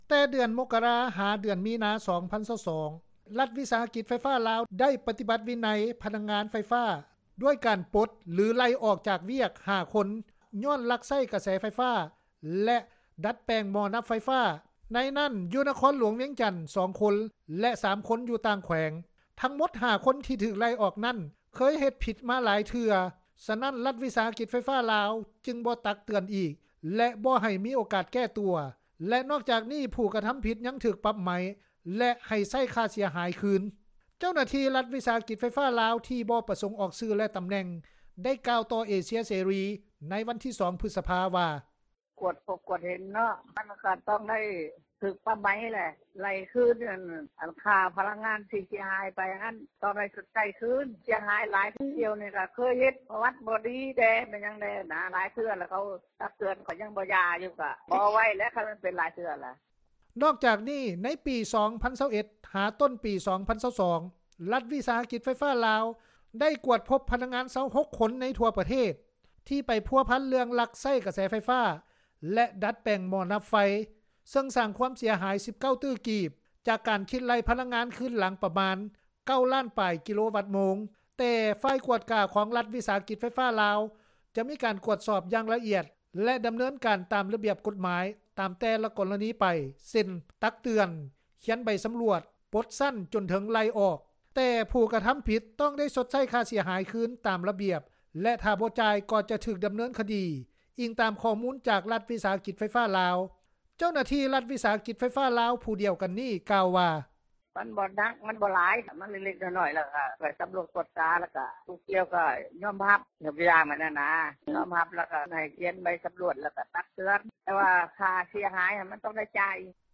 ຊາວບ້ານ ທ່ານນຶ່ງ ໄດ້ກ່າວ ໃນວັນທີ 2 ພຶສພາ ວ່າ:
ຊາວບ້ານອີກທ່ານນຶ່ງ ໄດ້ກ່າວວ່າ: